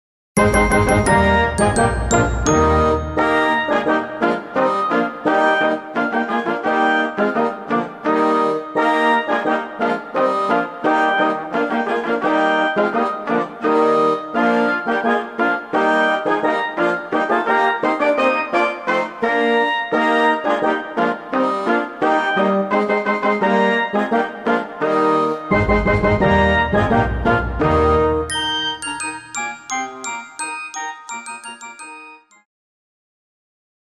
Christmas Karaoke Soundtrack
Backing Track without Vocals for your optimal performance.